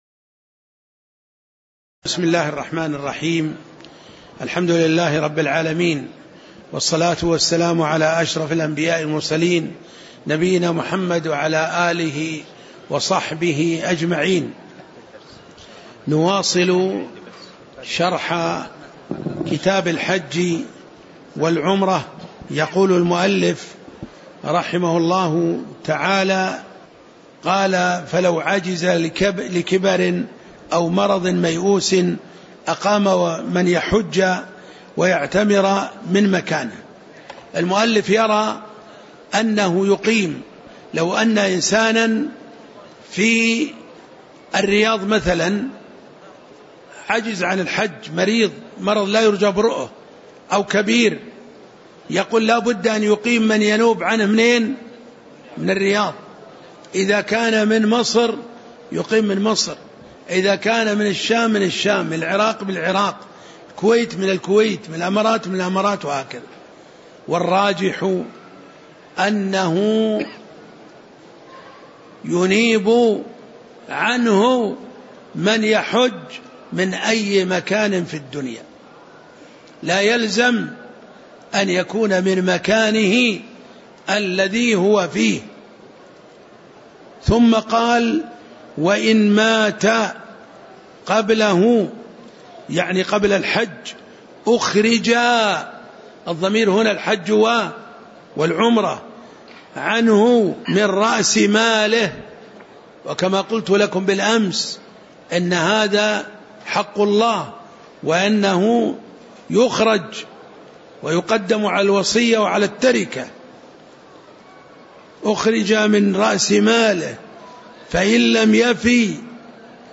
تاريخ النشر ١٨ شوال ١٤٣٩ هـ المكان: المسجد النبوي الشيخ